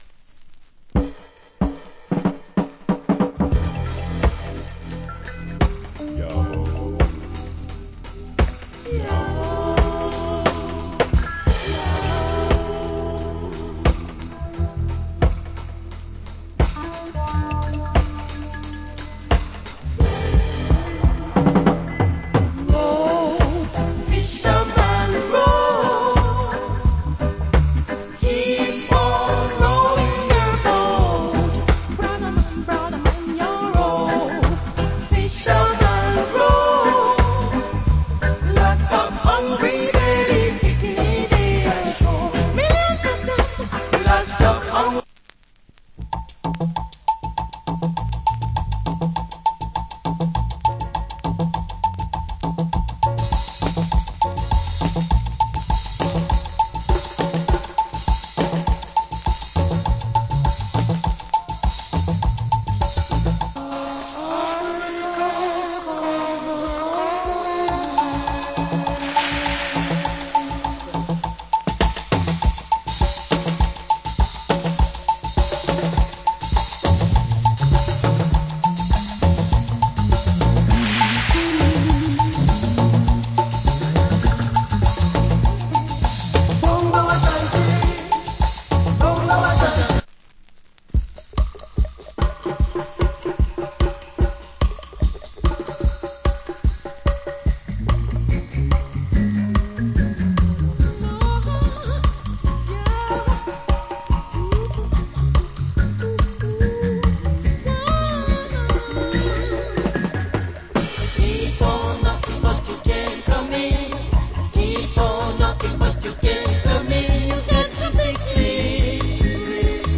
Nice Roots.